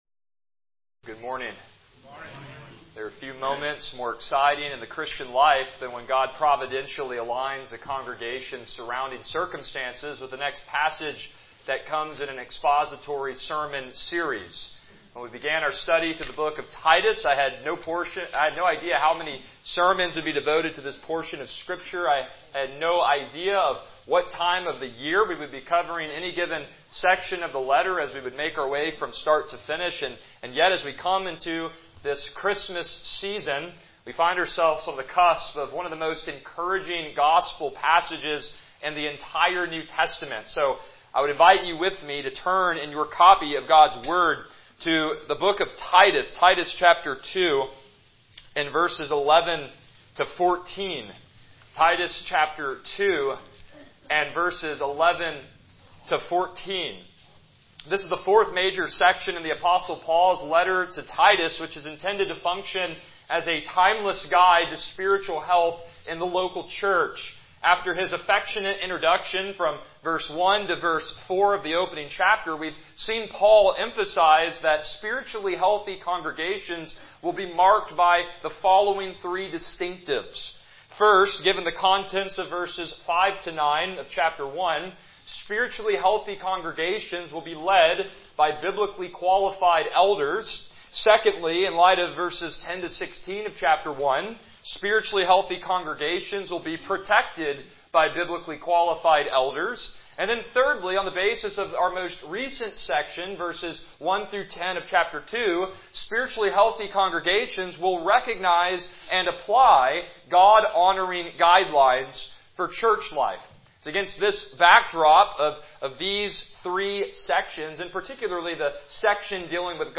Passage: Titus 2:11 Service Type: Morning Worship